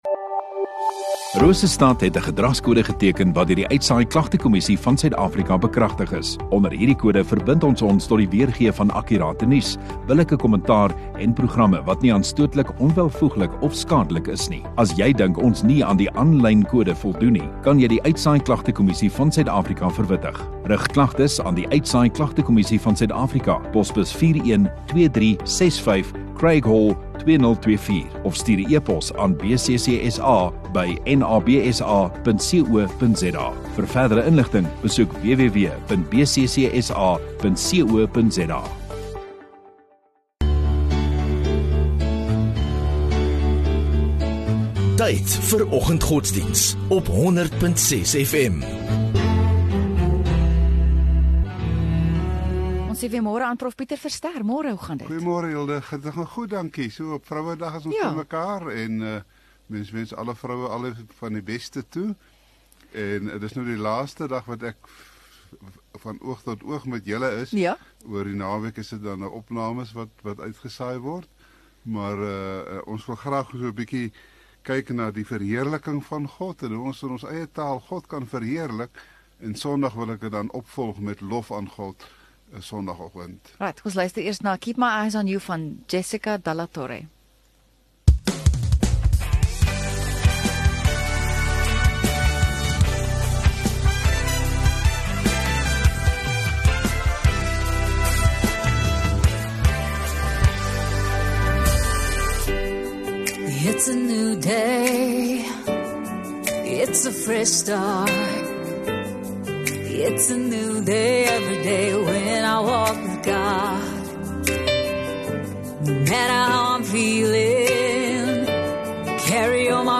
9 Aug Vrydag Oggenddiens